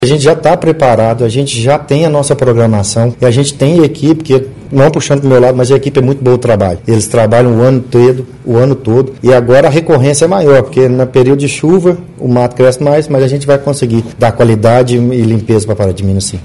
O responsável pela pasta, André Lara Amaral, explicou que mesmo contando com uma equipe enxuta, cuidará para que o trabalho seja bem divido, a fim de atender a toda a cidade.